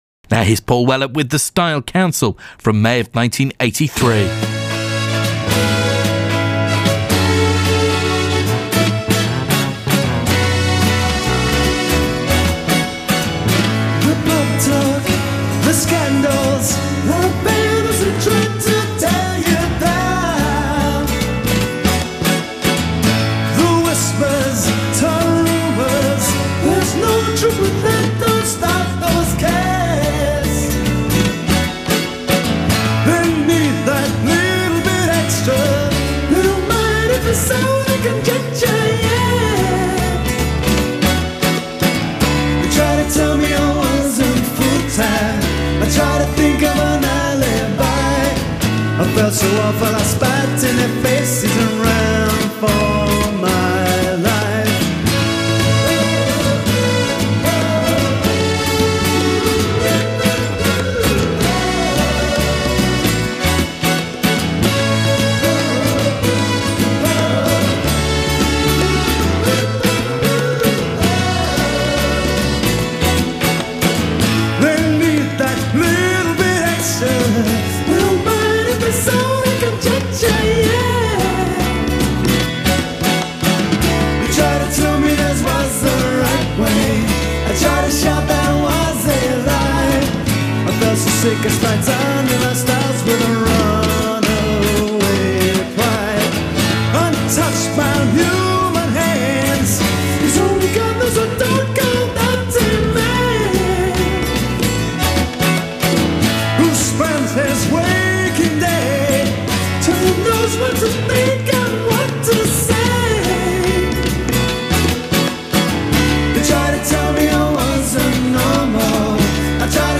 taking it down, making it soulful and funky.